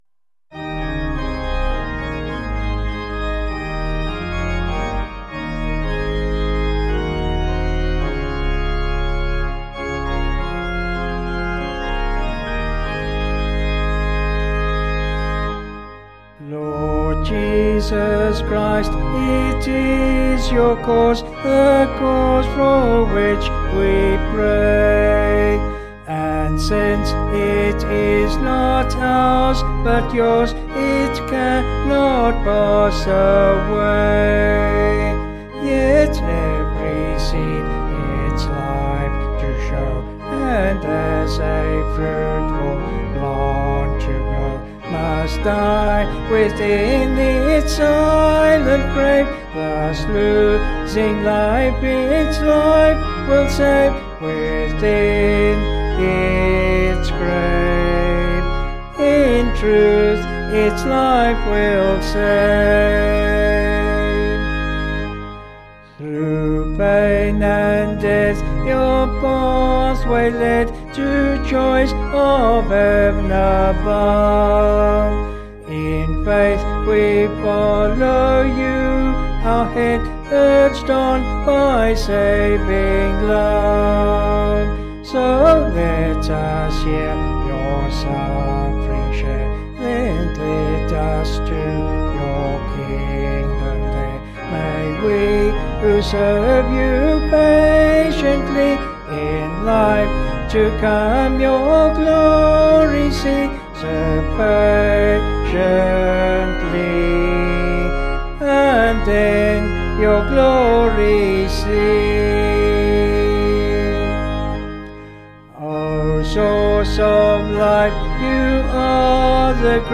(BH)   3/Ab
Vocals and Organ   264.5kb Sung Lyrics